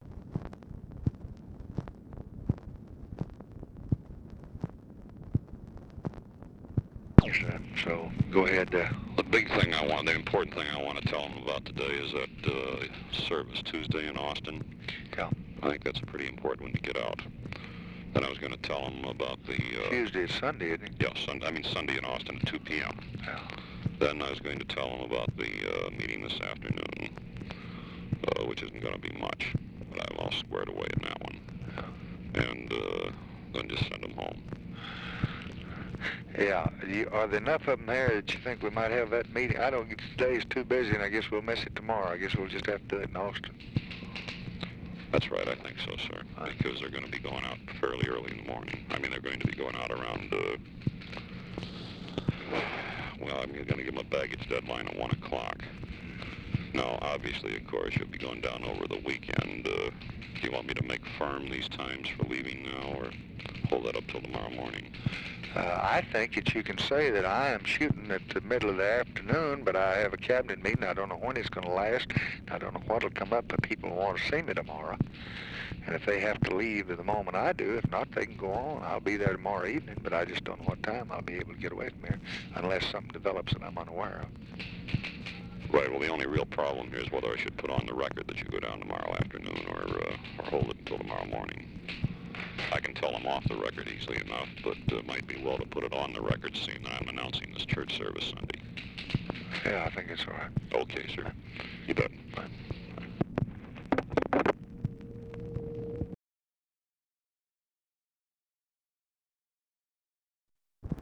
Conversation with GEORGE REEDY, November 18, 1964
Secret White House Tapes